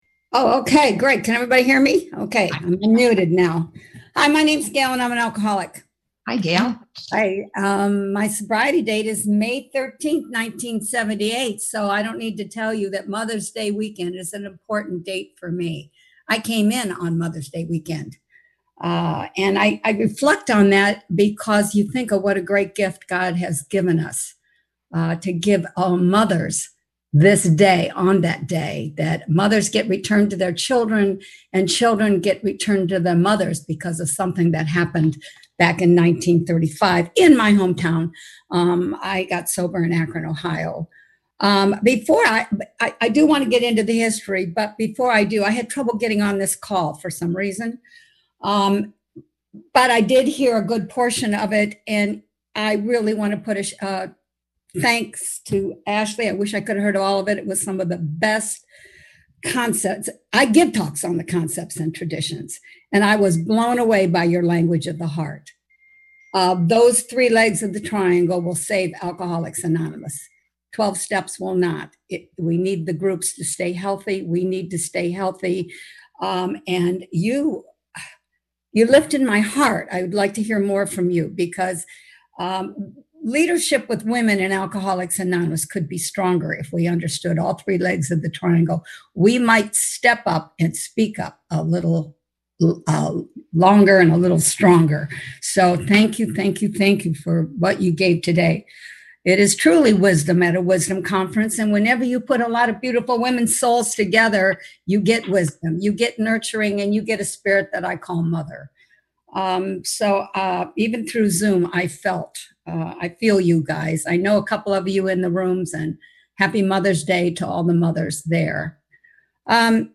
First Women In AA &#8211; Womens Wisdom Weekend &#8211